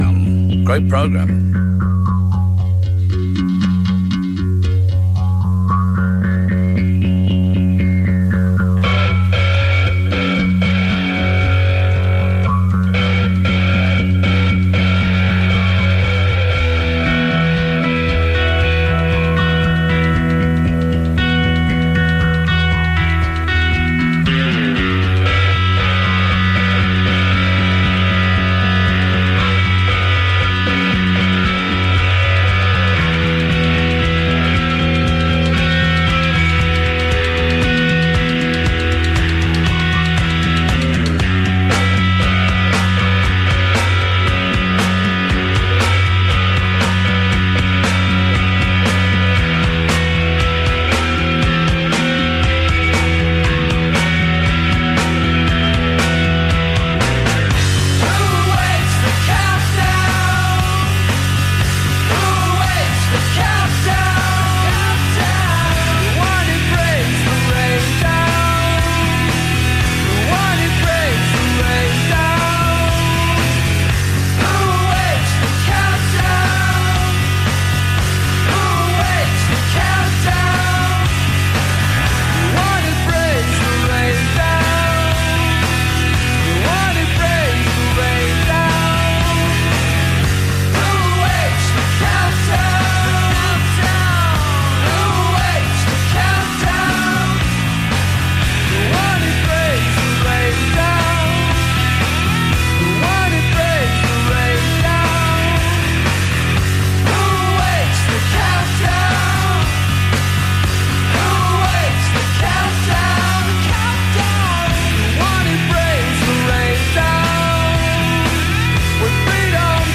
Indie From America.
Indie from Chicago to start the week.